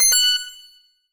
Menu_Navigation02_Error.wav